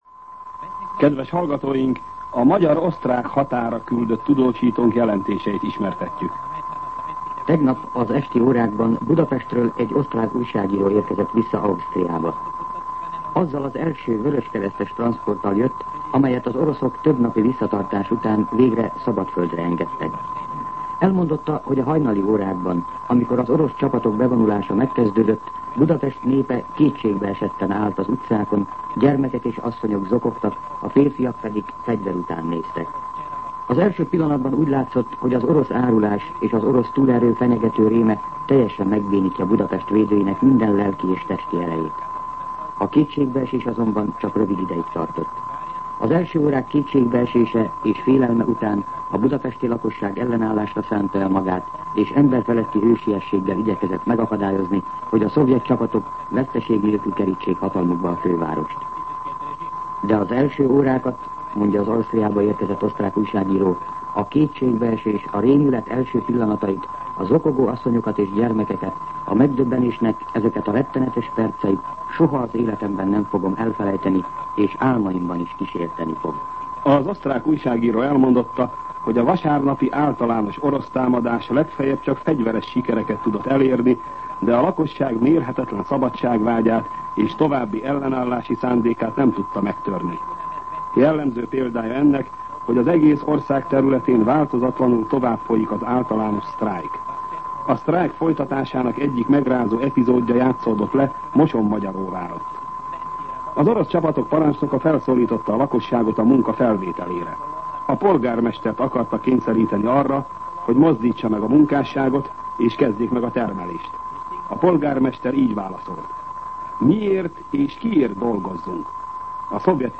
A magyar-osztrák határra küldött tudósító jelentése